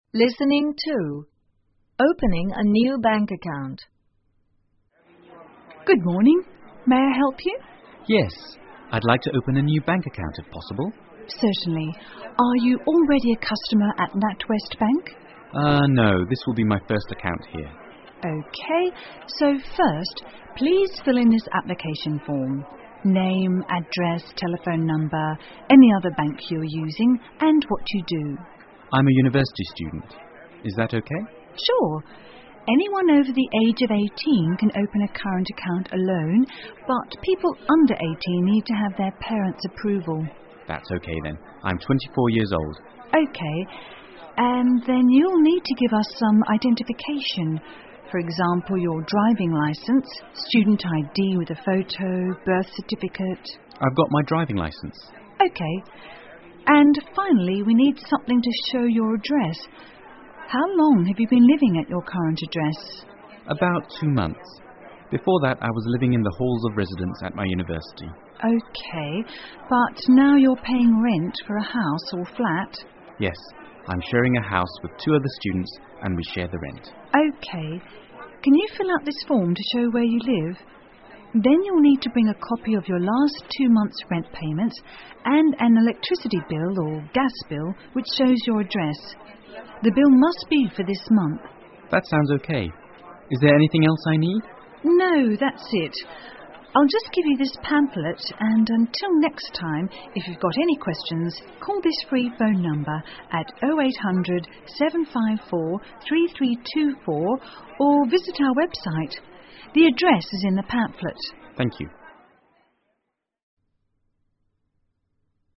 (C) A bank clerk and a customer